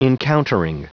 Prononciation du mot encountering en anglais (fichier audio)
Prononciation du mot : encountering